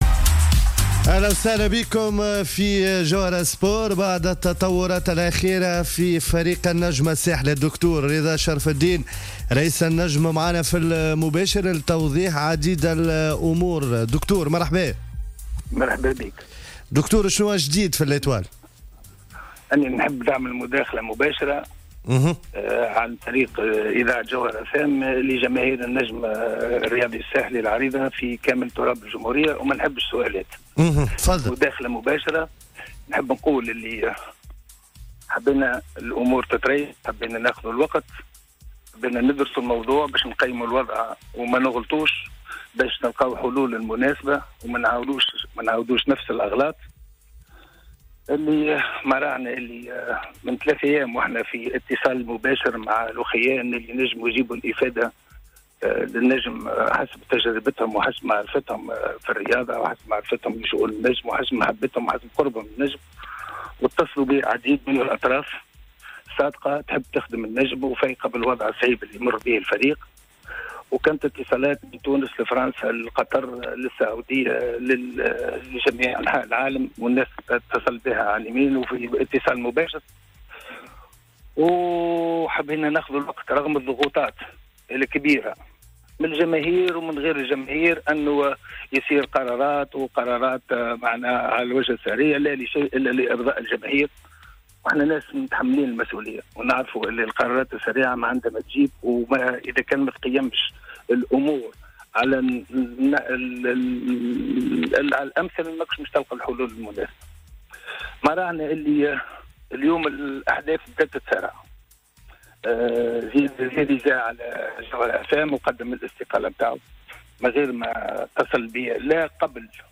خلال مداخلته مساء اليوم في النشرة الرياضية "الجوهرة سبور"